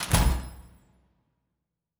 pgs/Assets/Audio/Fantasy Interface Sounds/Special Click 30.wav at master
Special Click 30.wav